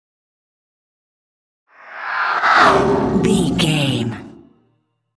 Creature whoosh horror
Sound Effects
Atonal
ominous
haunting
eerie
whoosh